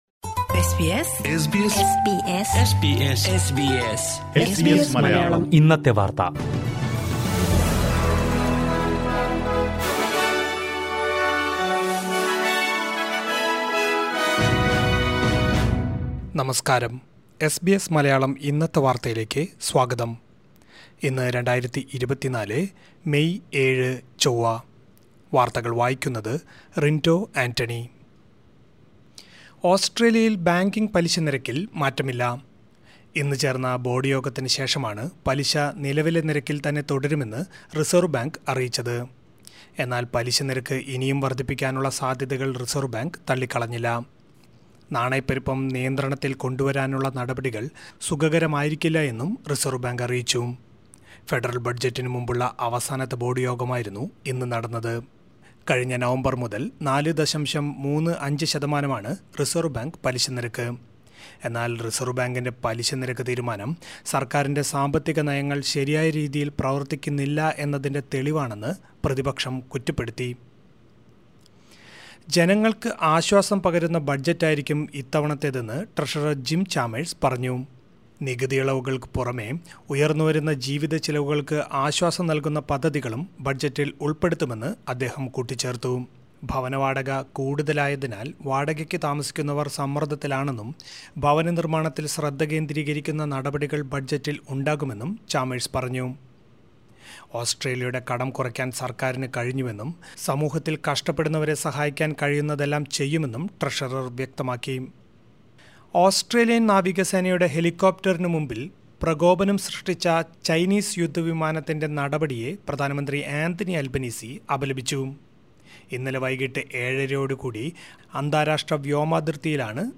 2024 മെയ് ഏഴിലെ ഓസ്‌ട്രേലിയയിലെ ഏറ്റവും പ്രധാന വാര്‍ത്തകള്‍ കേള്‍ക്കാം...